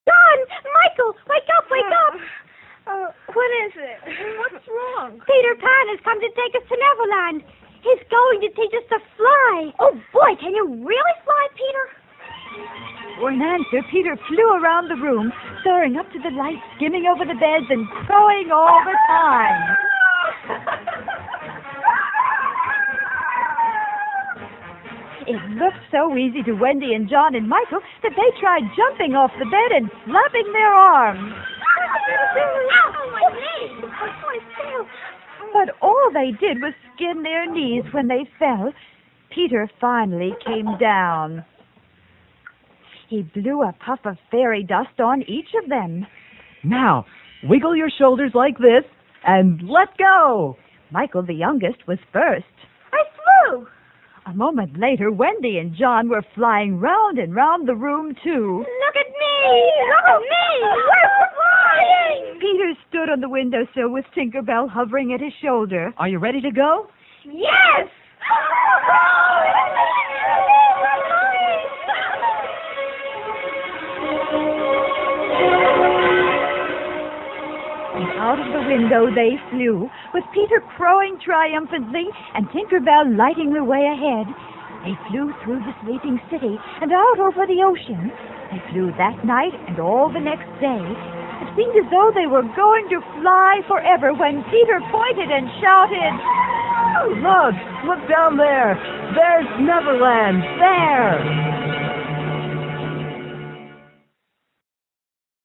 The shows were basically live-to-tape.
Someone rocked back and forth in a creaky old chair for the boat, and the crocodile was an alarm clock and mike covered over with that old metal wash-tub from the sound effects cabinet.